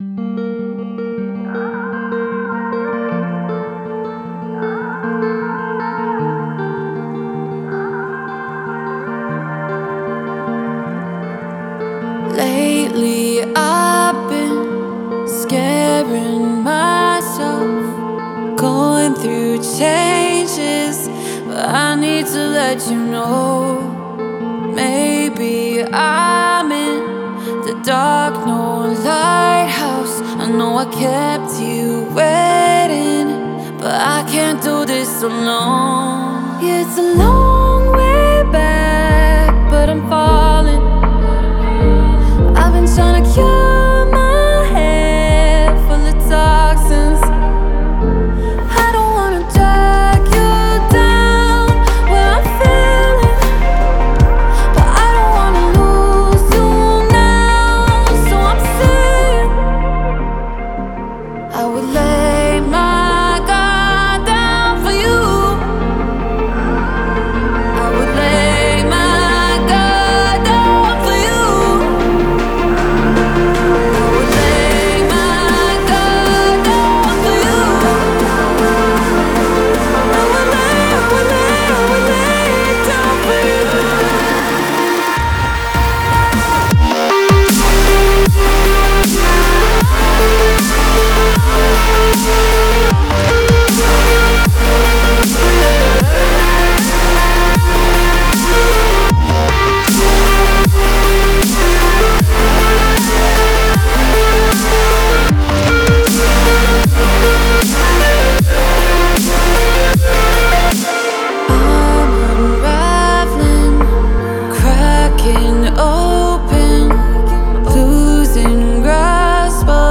это мощный трек в жанре EDM